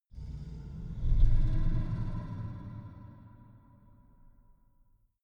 Gemafreie Sounds: Kreaturen